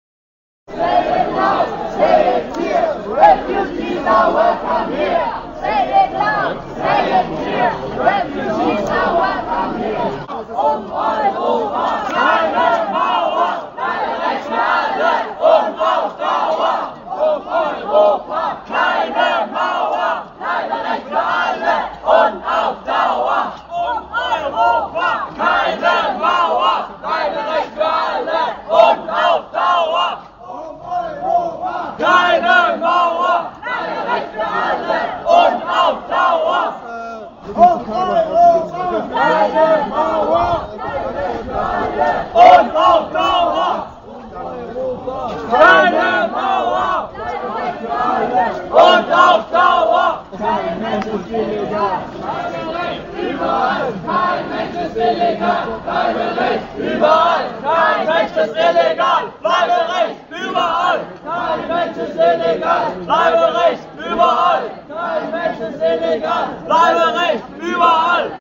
Demonstration „Freiheit stirbt mit Sicherheit“ & Straßentheater in Freiburg